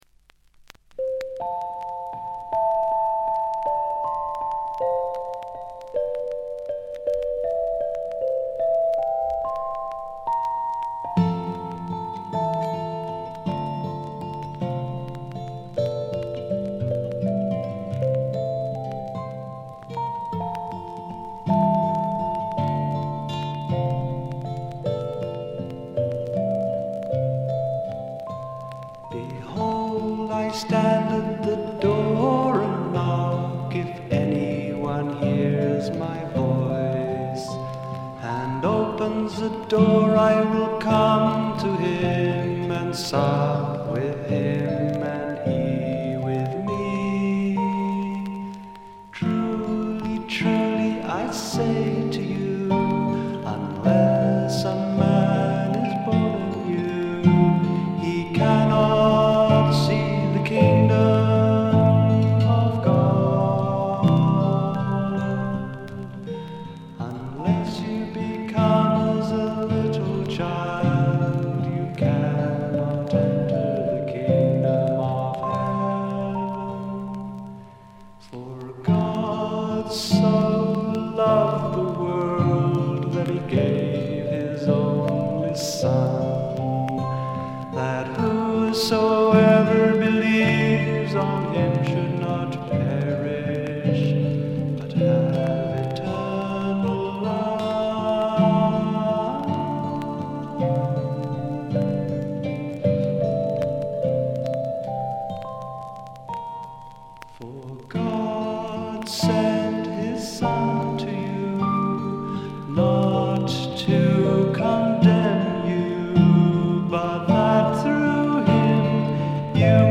バックグラウンドノイズ、チリプチ多め大きめ。
妖精フィメール入り英国ミスティック・フォーク、ドリーミー・フォークの傑作です。
霧深い深山幽谷から静かに流れてくるような神秘的な歌の数々。
それにしても録音の悪さが幸いしてるのか（？）、この神秘感は半端ないです。
試聴曲は現品からの取り込み音源です。